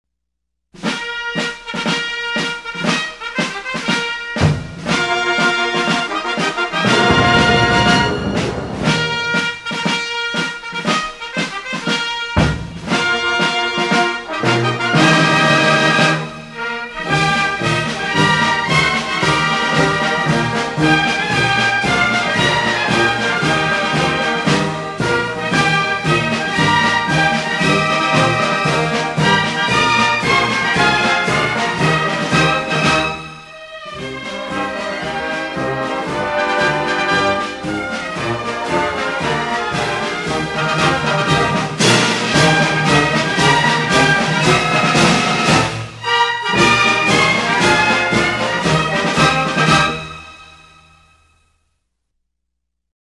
Cuban_anthem.mp3